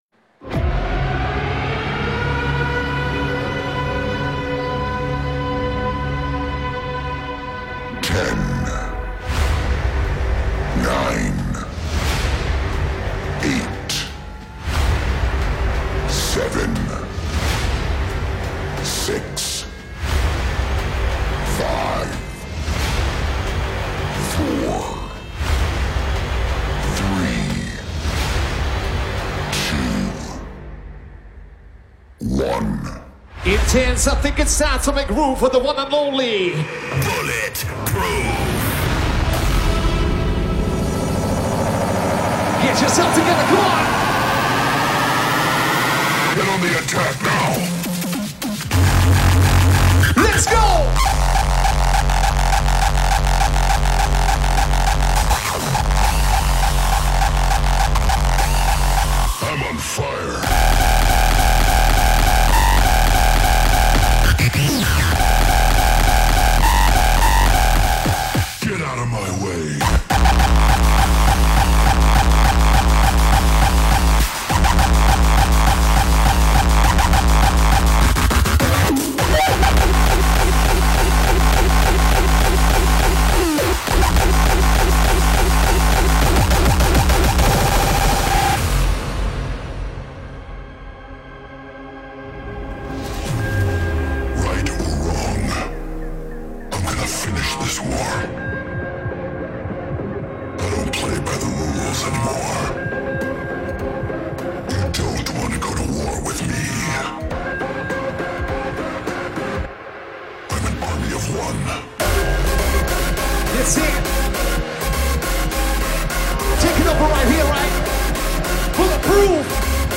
Genre: Hardstyle